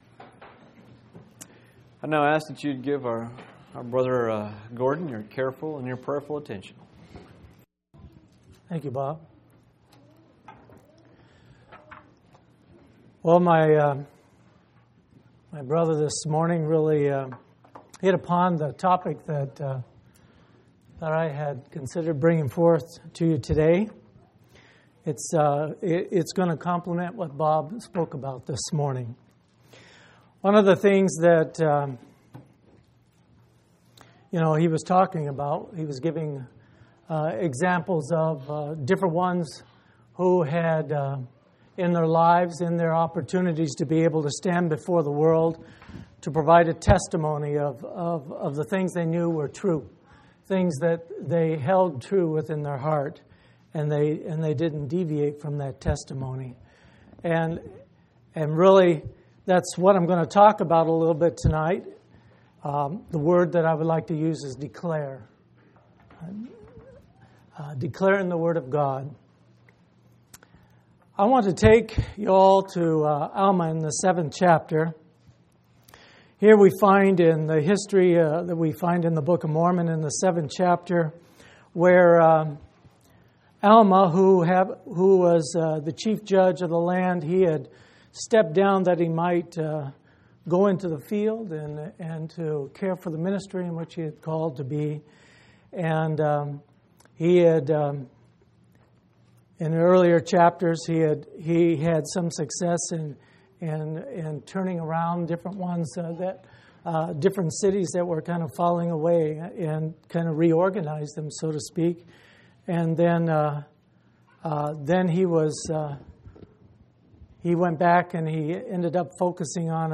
11/20/2005 Location: Phoenix Local Event